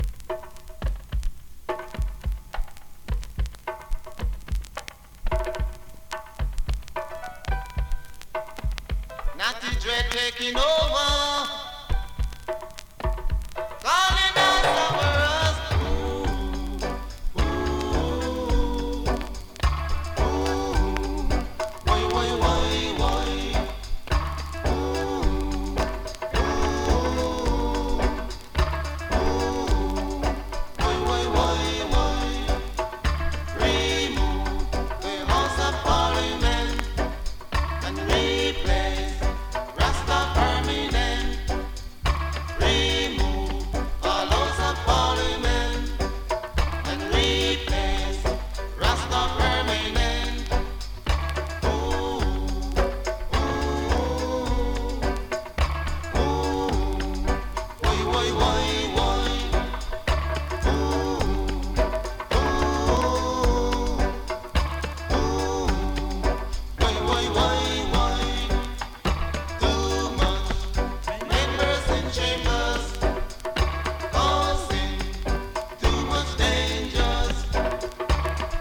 スリキズ、ノイズ比較的少なめで